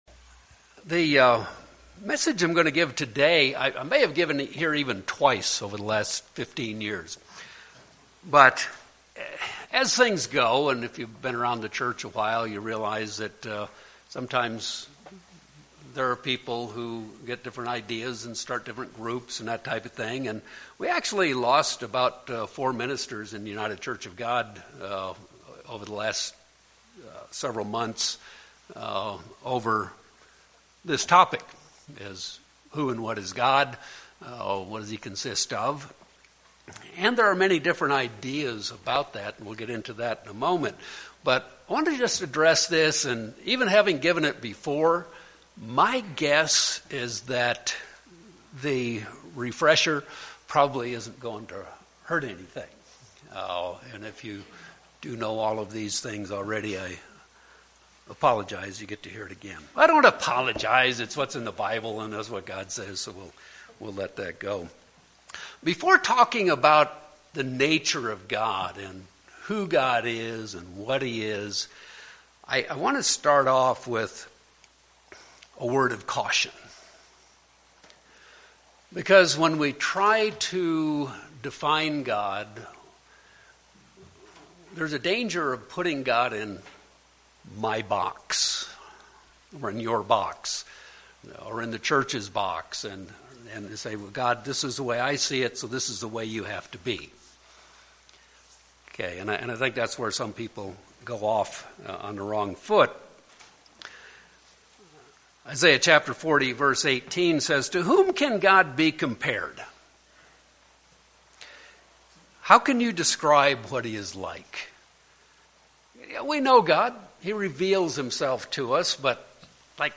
However, there is not unanimity of understanding as to just who and what God is. This sermon will explore what the Scriptures reveal about the Nature of God.